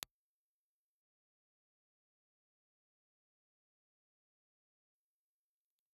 Ribbon
Impulse Response file of the front of the RGD ribbon microphone.
RGD_Ribbon_IR_Front.wav